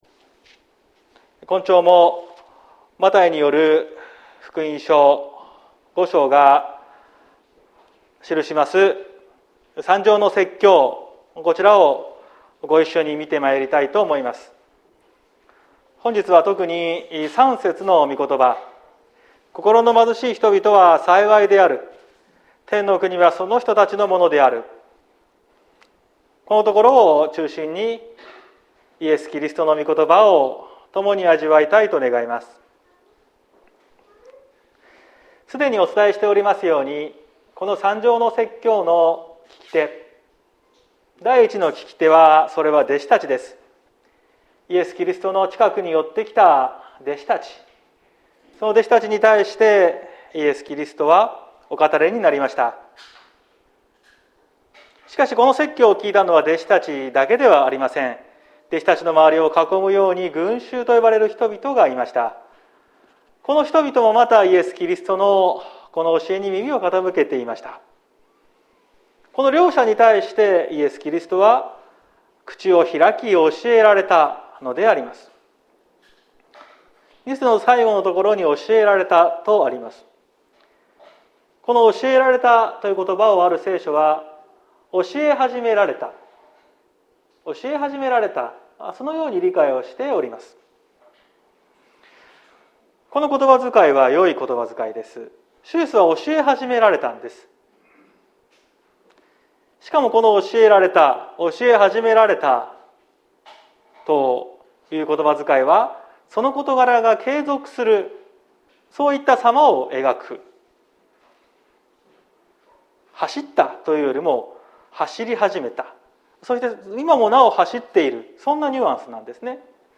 2022年06月12日朝の礼拝「幸福宣言」綱島教会
説教アーカイブ。